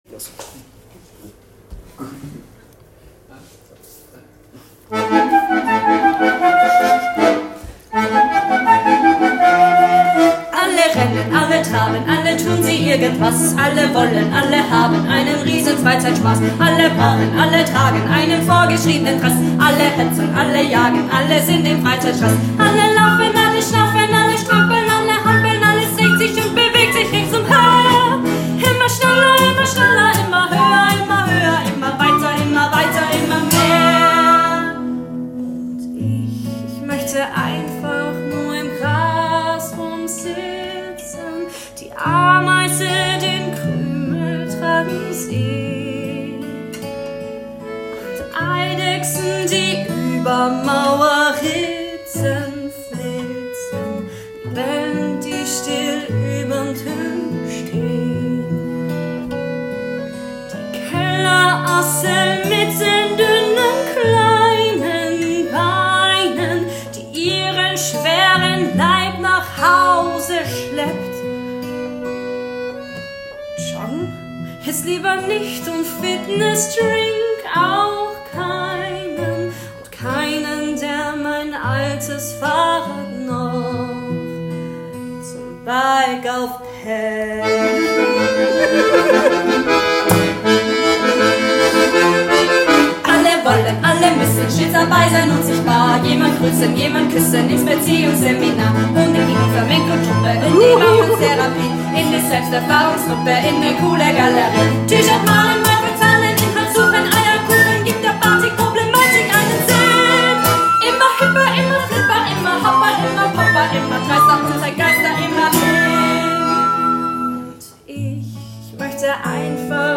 in einem sehr stimmungsvollen Arrangement
"Alle Rennen" performt von der Gottesdienst-Band (Probenaufnahme).